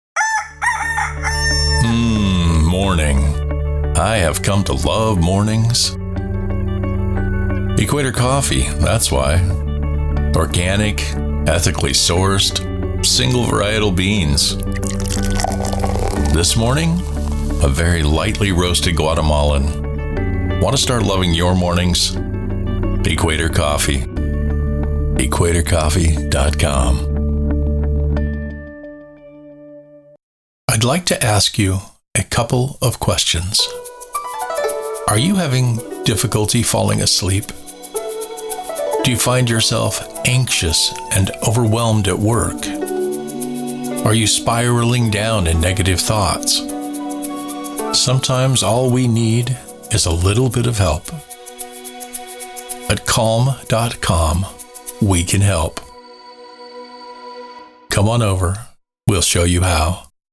Commercial VO
Two ads